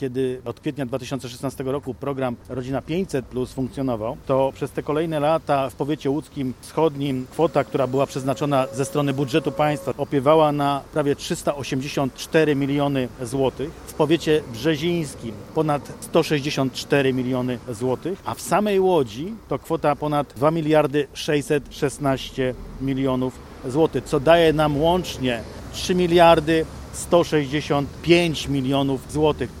Wicemarszałek województwa łódzkiego Piotr Adamczyk podsumował, ile pieniędzy od początku działania programu przeznaczono na ten cel w trzech powiatach: łódzkim wschodnim, brzezińskim i Łodzi.